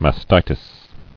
[mas·ti·tis]